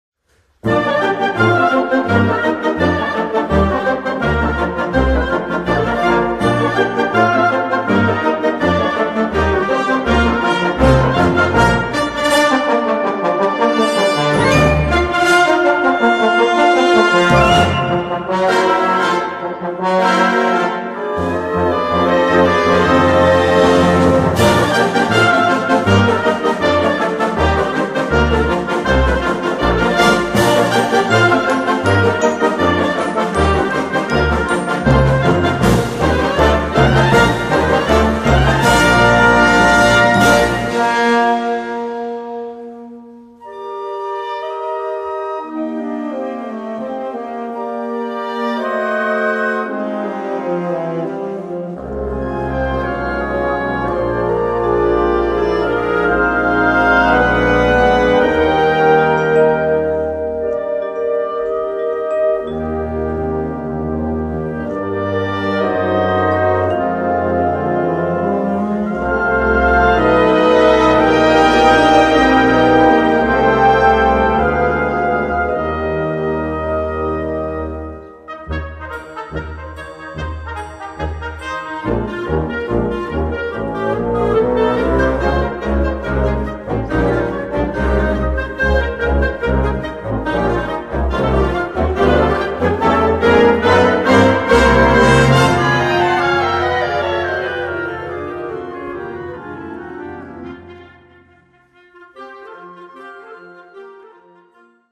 Gattung: Concert Band
Besetzung: Blasorchester